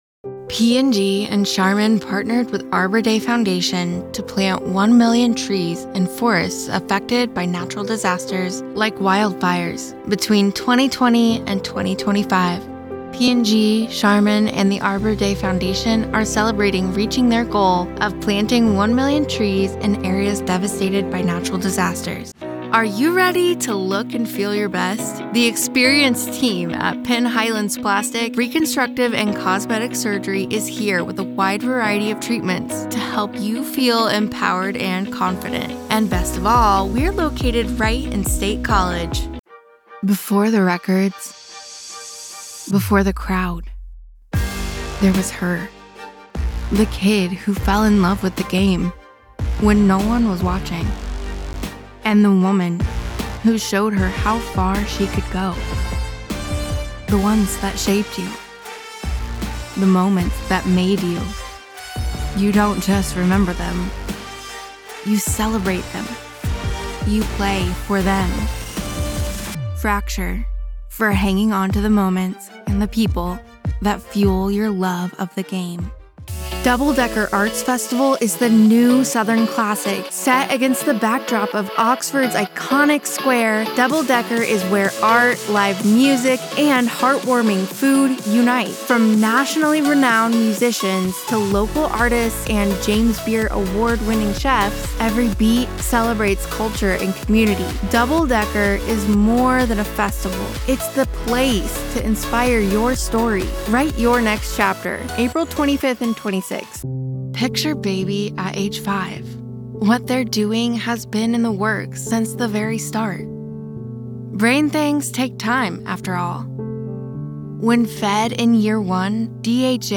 Relatable, conversational, real person, believable, soft, warm, sincere, sensual, Persuasive, Friendly, relatable, smooth, funny, quirky,
Full-time female American voice actor with soft